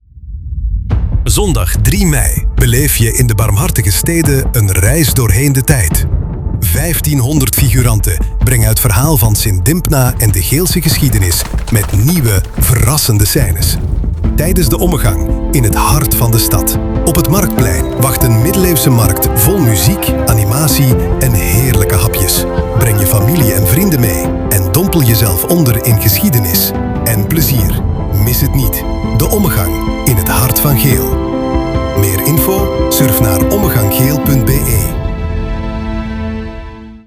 Radio commercial - Sint Dimpna Ommegang | AINGELS
Cinematic radiospot voor de Ommegang in Geel